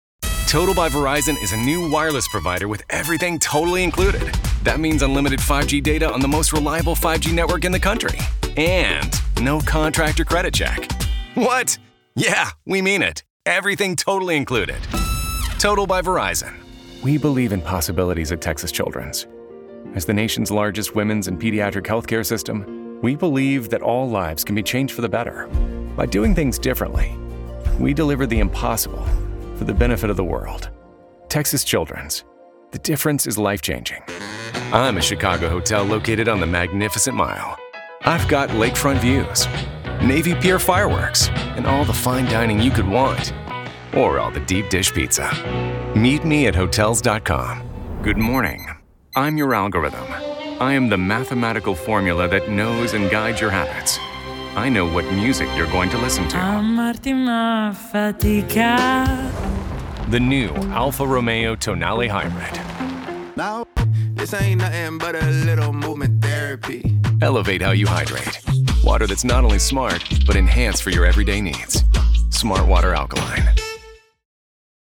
Male Voice Over Talent, Artists & Actors
Adult (30-50)